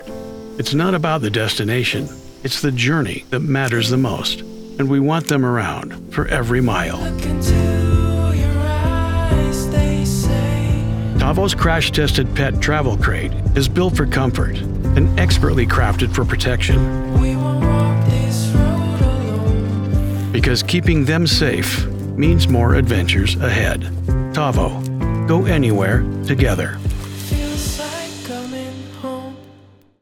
• Authentic and relatable — a real human presence, not a polished announcer veneer
• Gritty and grounded — forged in the mountains, with a modern Western edge
• Warm and trustworthy — the voice of a guide, not a salesman
Commercial Demo